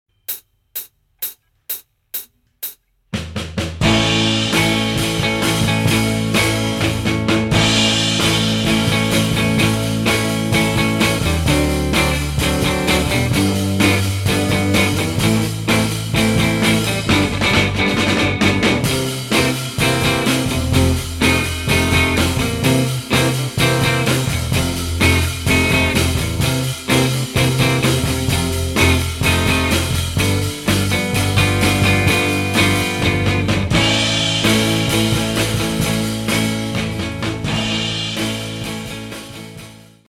No Backing Vocals. Professional Karaoke Backing Tracks.
This is an instrumental backing track cover.
Key – A
No Fade